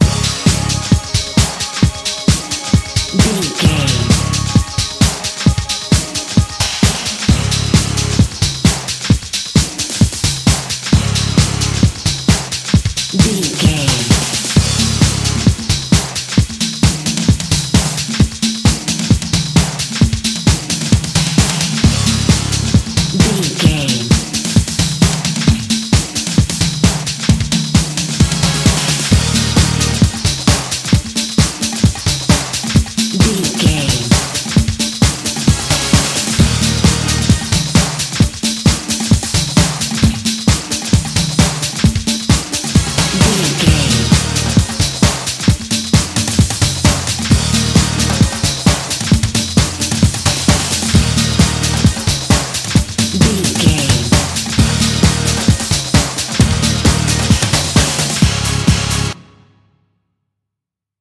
Uplifting
Ionian/Major
drum machine
synthesiser
bass guitar
Eurodance